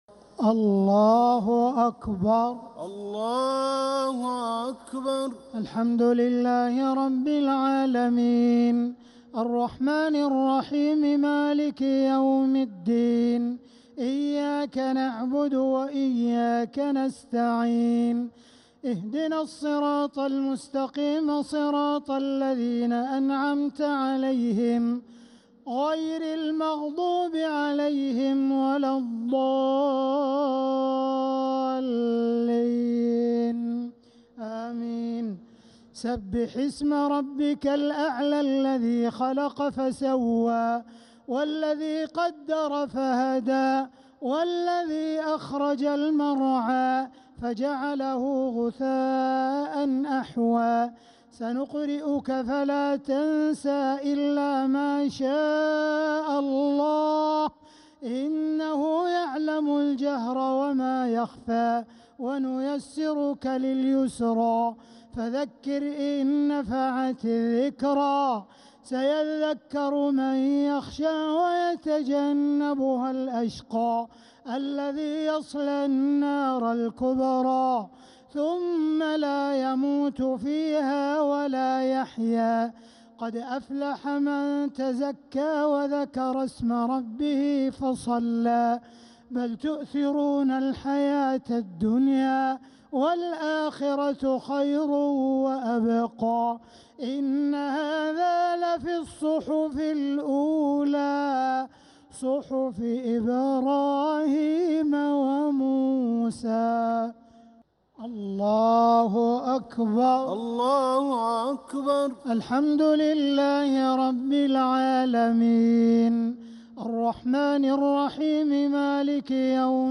صلاة الشفع و الوتر ليلة 1 رمضان 1447هـ | Witr 1st night Ramadan 1447H > تراويح الحرم المكي عام 1447 🕋 > التراويح - تلاوات الحرمين